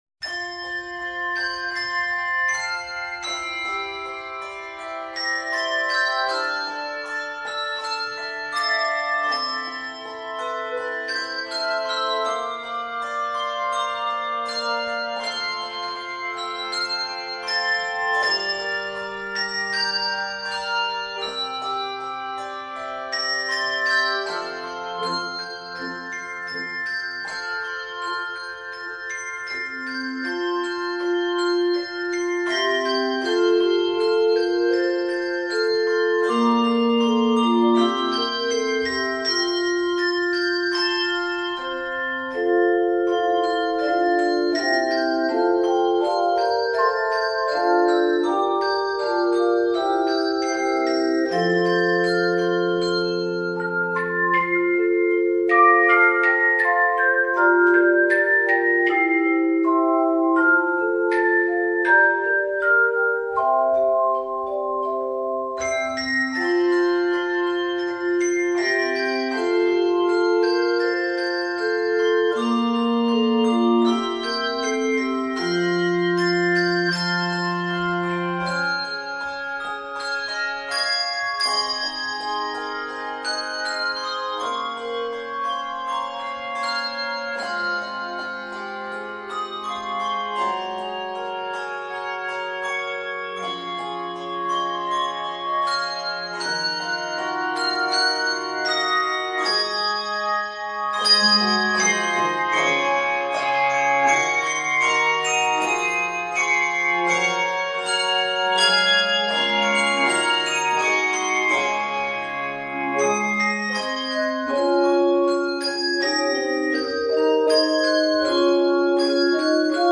Voicing: Handbells 3-5 Octave